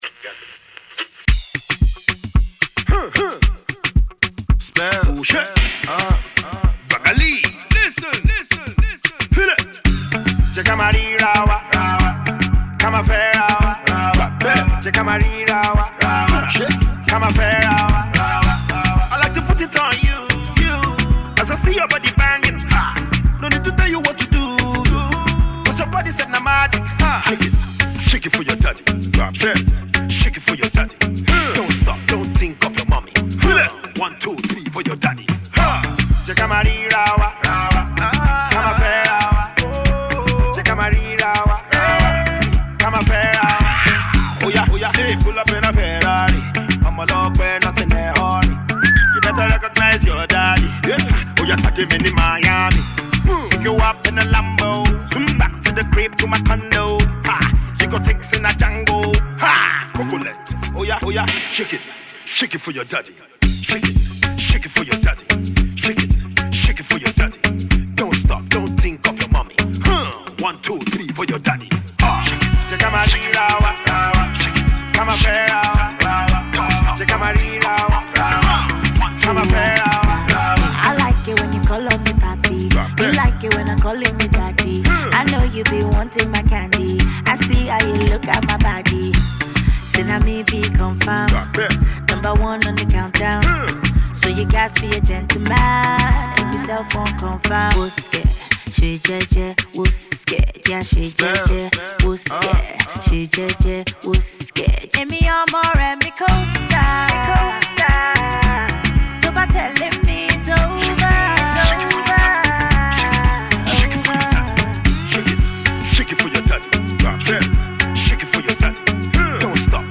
smooth tune
Afrobeats